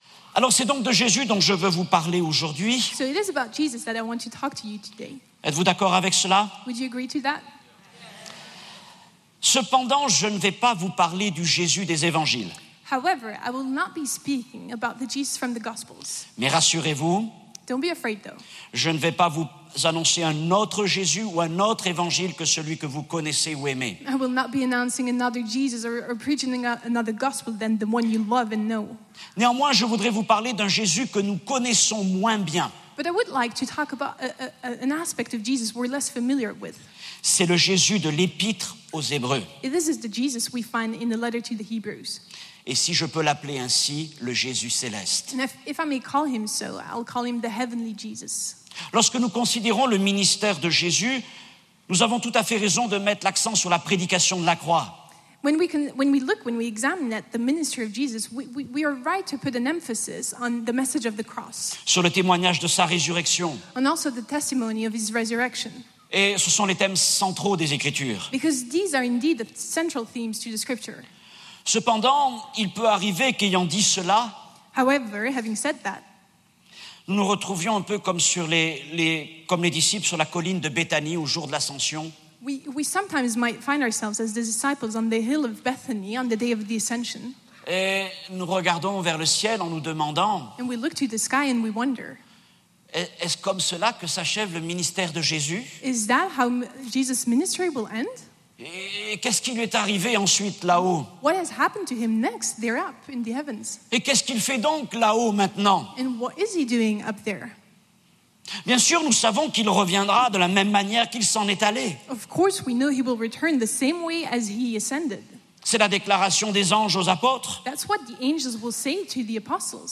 The Heavenly Ministry Of Jesus | Times Square Church Sermons